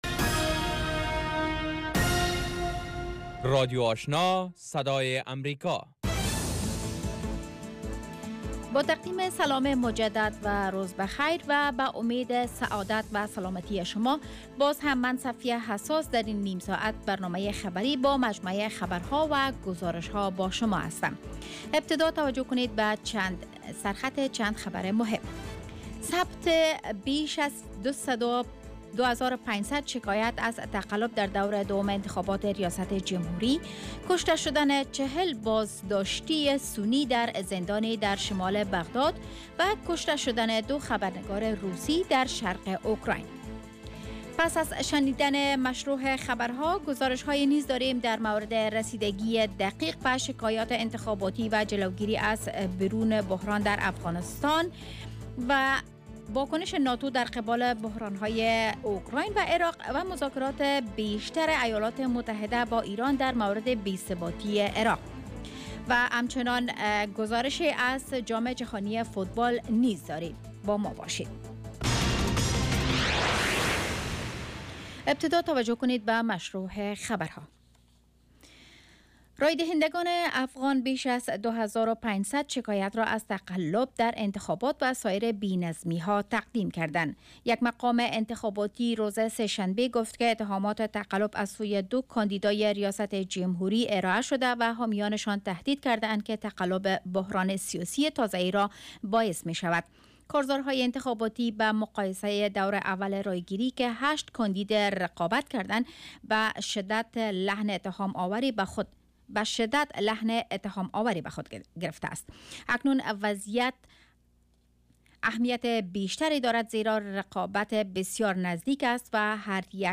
morning news show second part